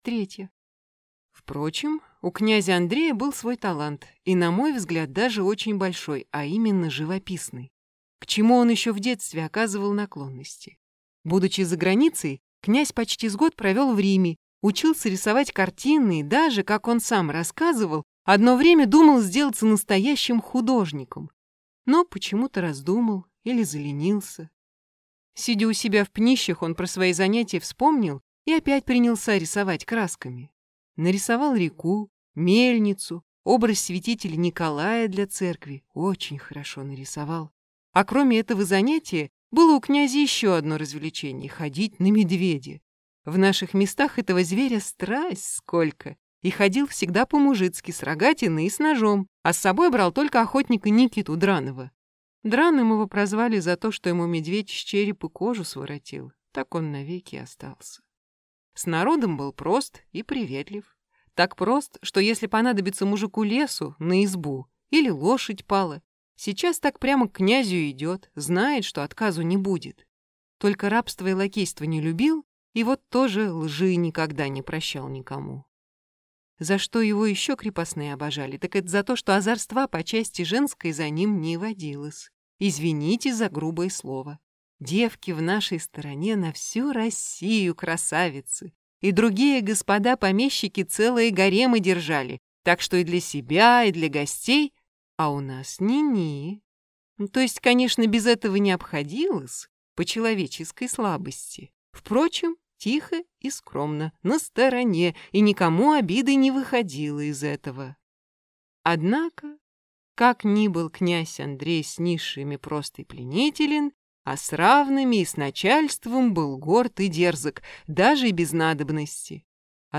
Aудиокнига Картина